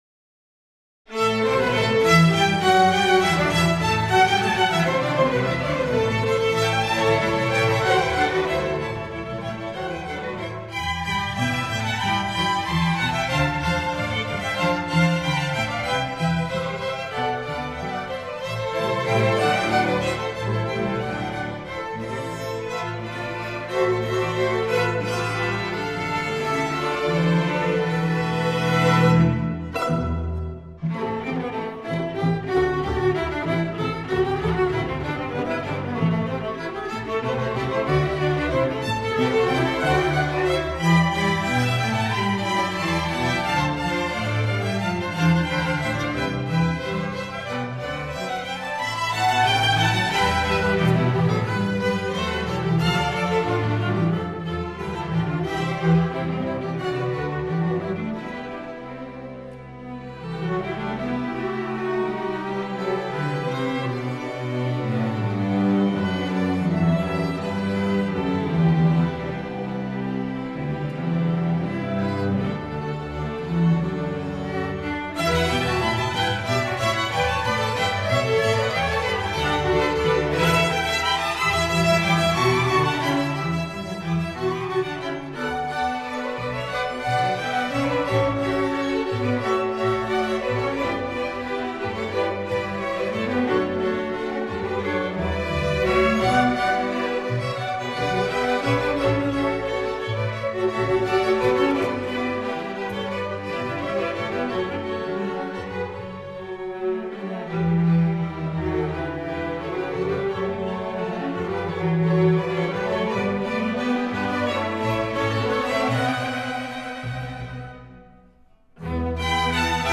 Streichorchester (6)